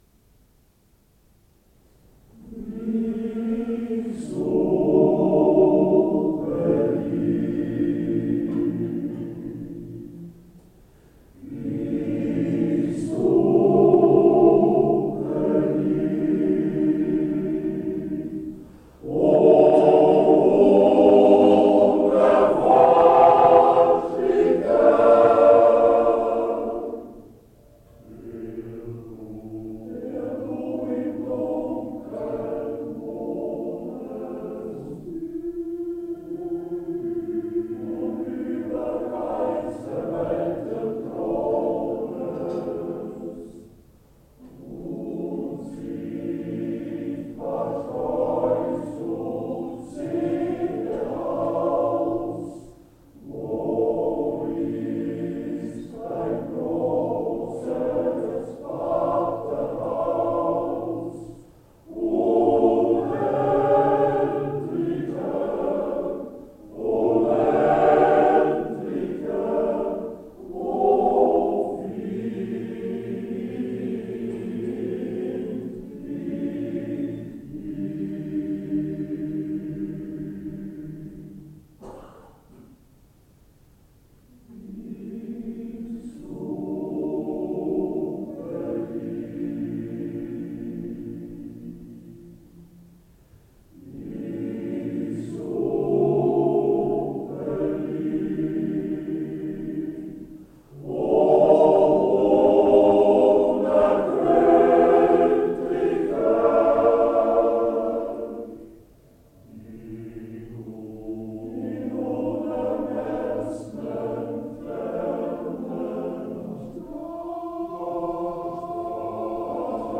04-forschen-nach-gott-chor.ogg